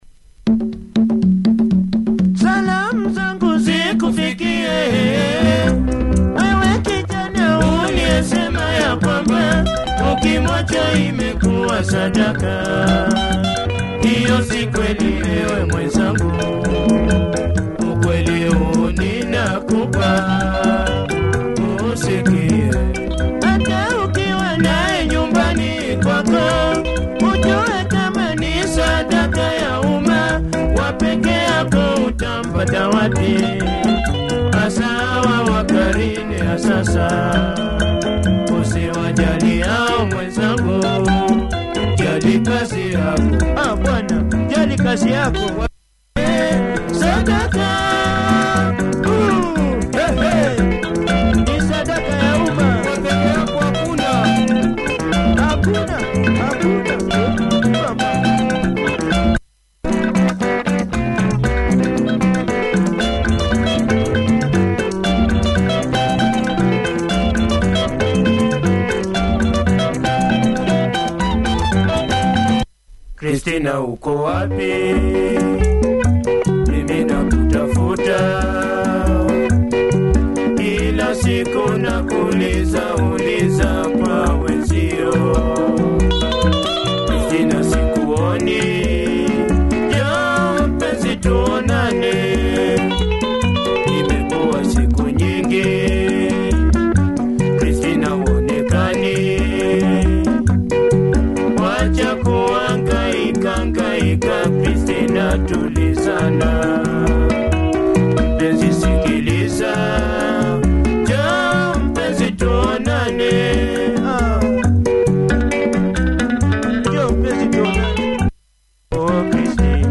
Nice percussion! Clean copy check audio! https